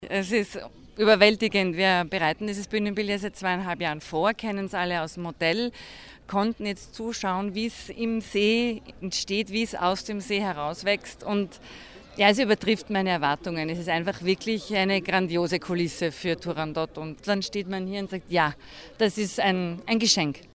O-Töne "Turandot"-Richtfest - News
bregenz_richtfest-turandot-news.mp3